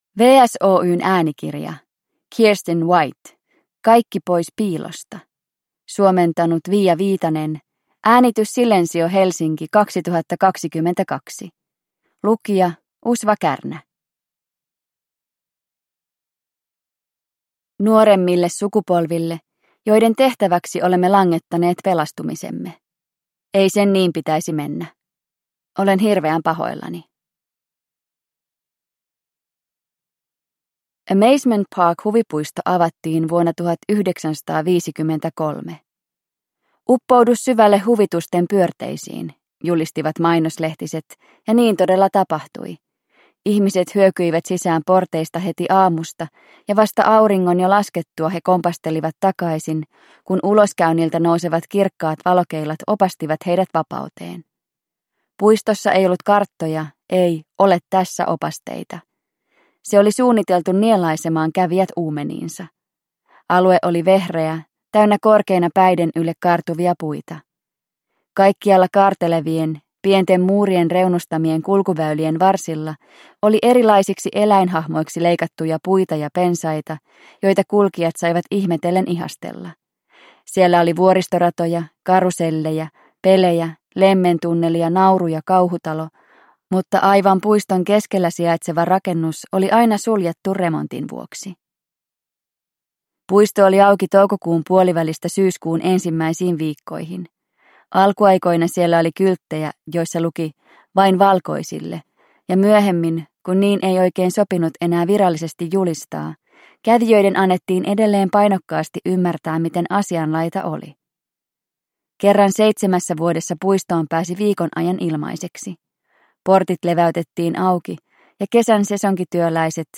Kaikki pois piilosta – Ljudbok – Laddas ner